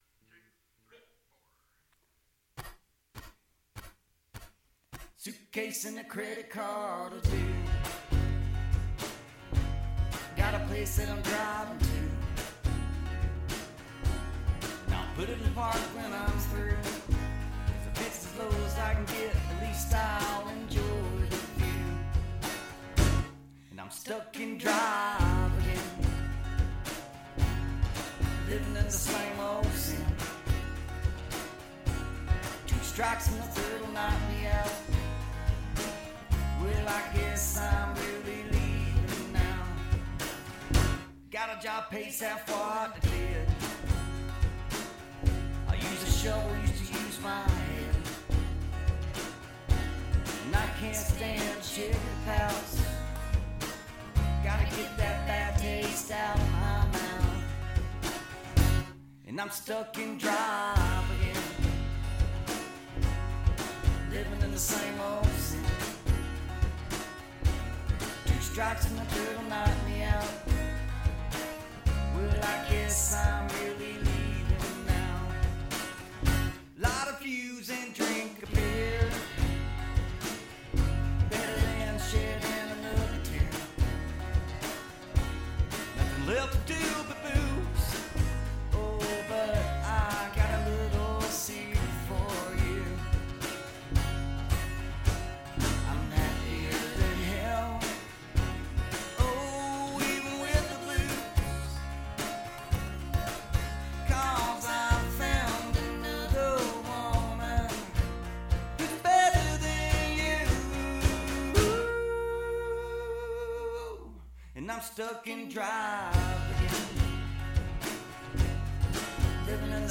acoustic trio Jackson Walls